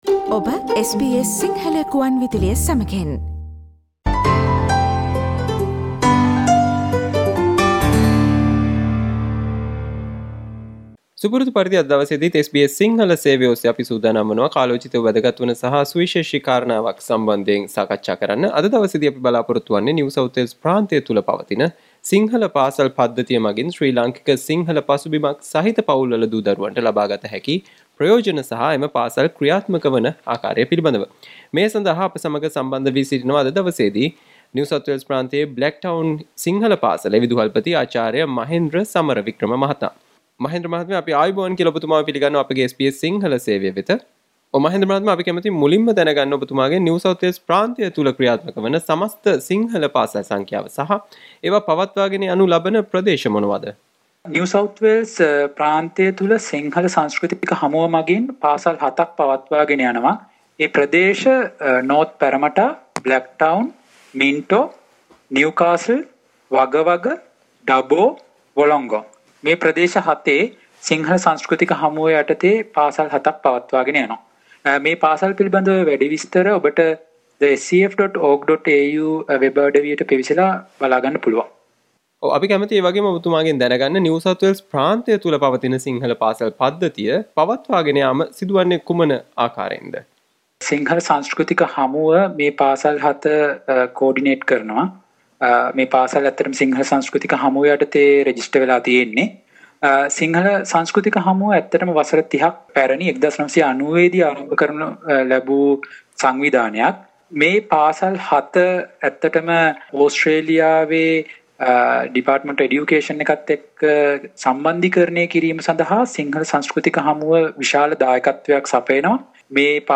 SBS Sinhalese interview